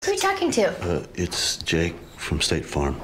state-farm-commercial-state-of-unrest-jake-mp3cut.mp3